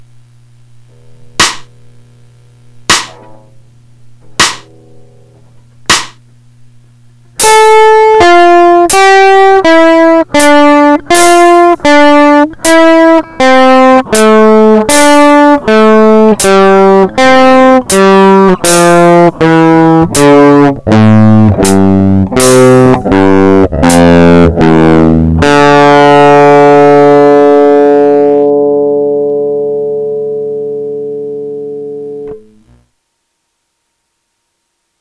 音程が下降していくたびに小指が先行していくので大変です。